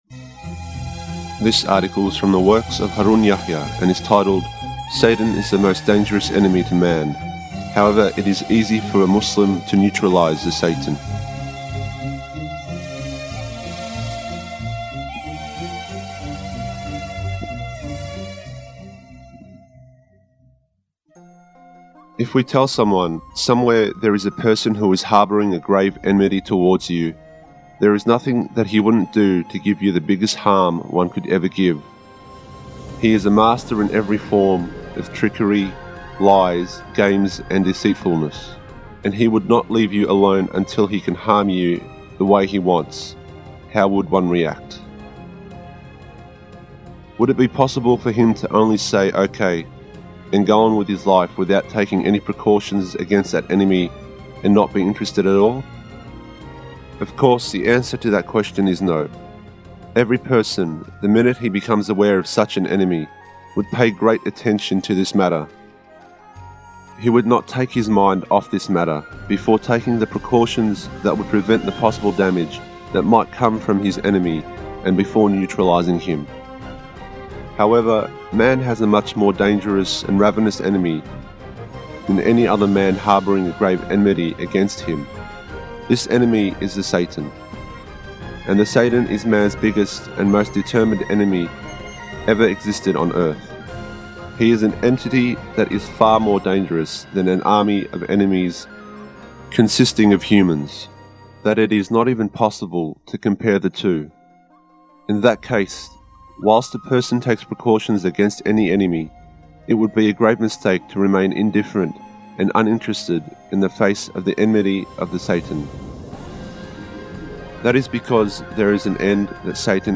Excerpt from Mr. Adnan Oktar's Live Interview on Kahramanmaraş Aksu TV dated February 3rd, 2011 'Were My Signs not recited to you and did you not deny them?' (Surat al-Muminun, 105)Almighty Allah says; I was reciting My verses to you but you denied them...